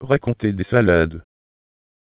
Synthese de la parole - le Démonstrateur CNETmultilingue